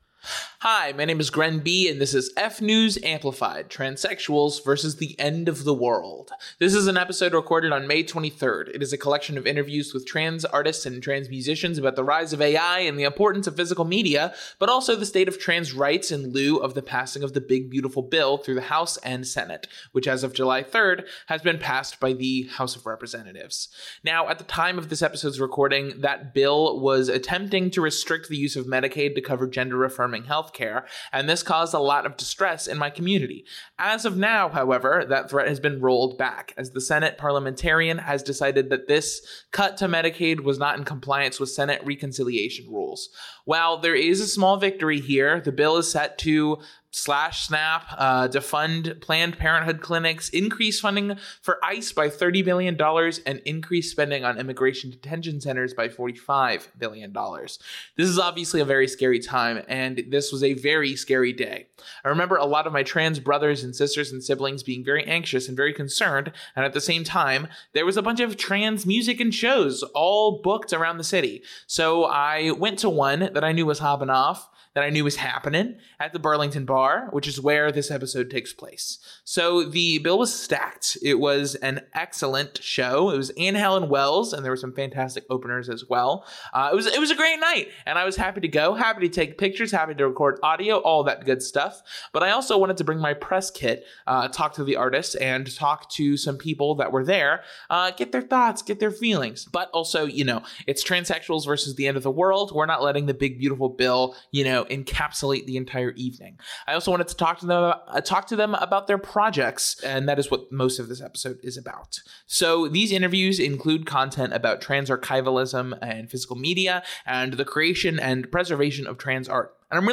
It is a collection of interviews with trans artists and trans musicians about the rise of AI and the importance of physical media, but also the state of trans right in lieu of the the passing of the Big Beautiful Bill through the house and senate, which as of July 3rd, has been passed by the house of representatives.
I remember a lot of my trans brothers and sisters and siblings being very anxious and very concerned, and at the same time there was a bunch of trans music and shows books all around the city So I went to one that I knew was hopping off, that I knew was happening, at the Burlington Bar, which is where this episode takes place.